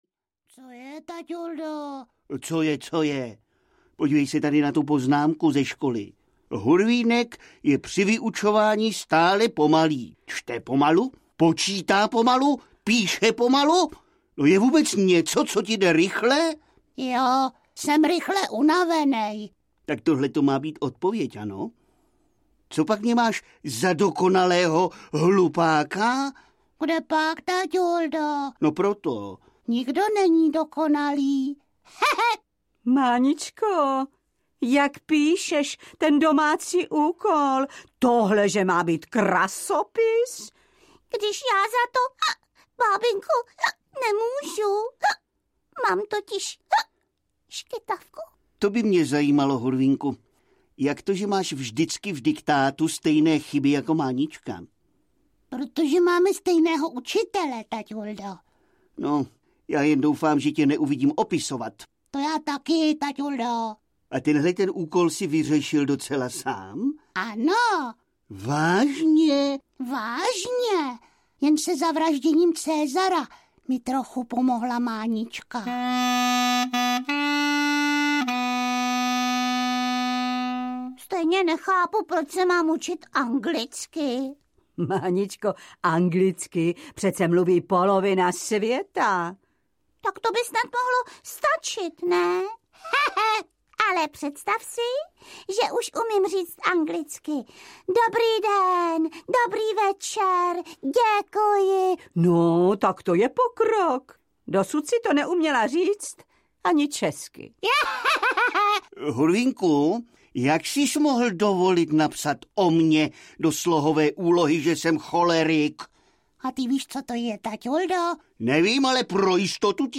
Hurvínkovo chichotání 2 audiokniha
Ukázka z knihy